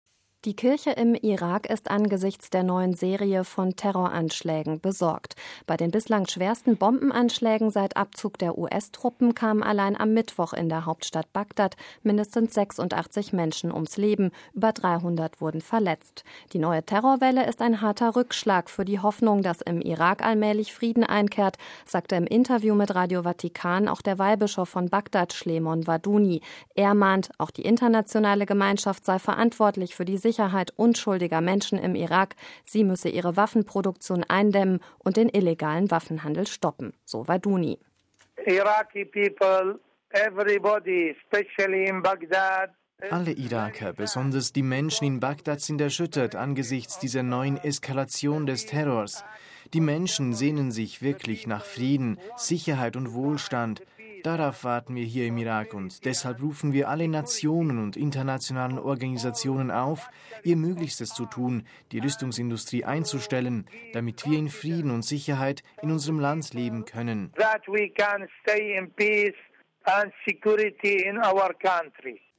Das sagte im Interview mit Radio Vatikan auch der Weihbischof von Bagdad, Shlemon Warduni.